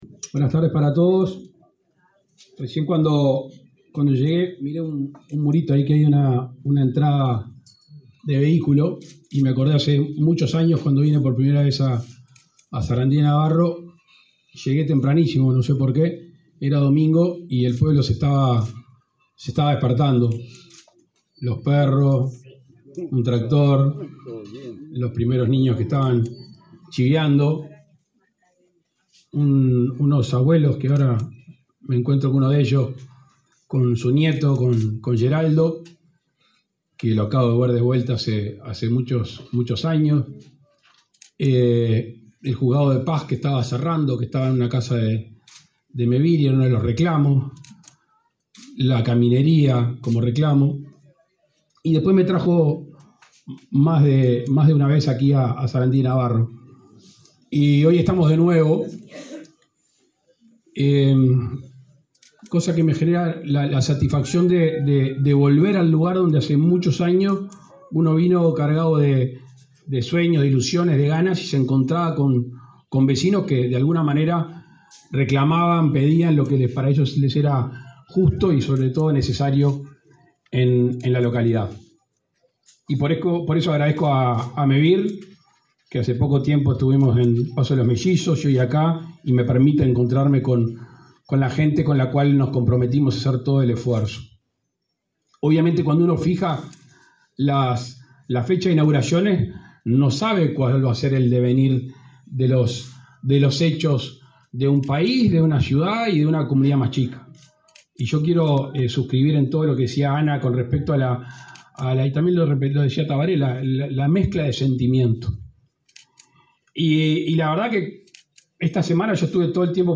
Palabras del presidente de la República, Luis Lacalle Pou
Con la presencia del presidente de la República, Luis Lacalle Pou, se realizó, este 11 de noviembre, la inauguración de 17 viviendas de Mevir en la